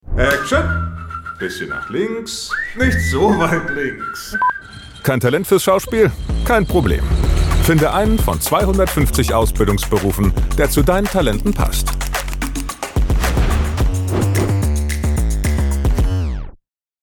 Sprecher und Stimmen von Manifest anhören, kostenlos Angebot einholen, günstig aufnehmen.